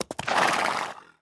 pony_stop.wav